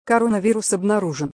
Коронавирус выявлен женский голос